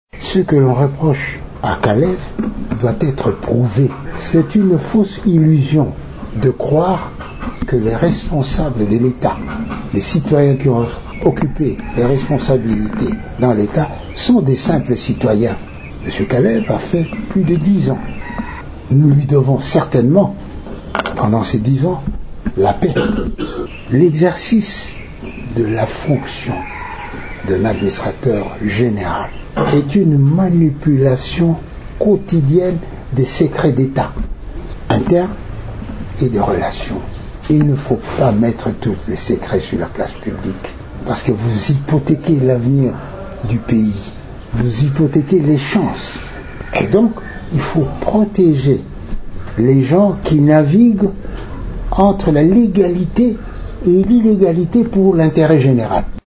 Devant la presse mercredi 17 mars à Kinshasa